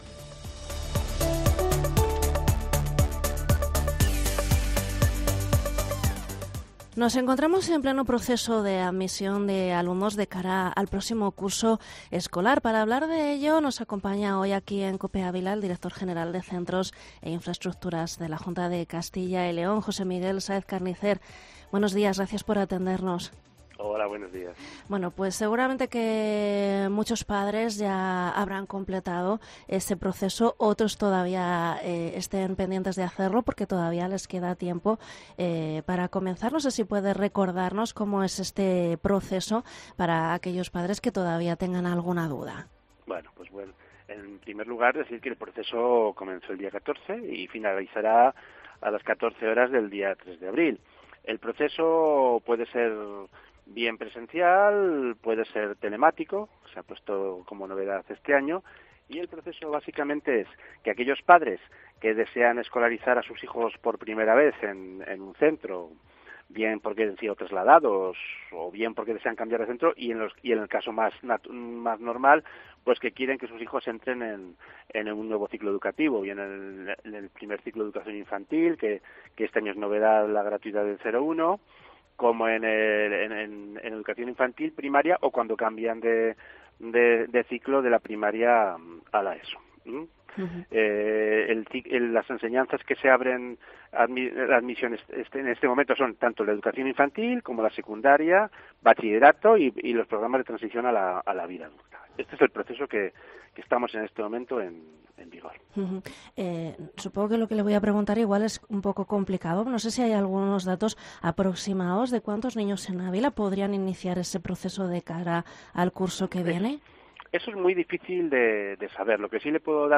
ENTREVISTA al director general de Centros e Infraestructuras, José Miguel Sáez Carnicer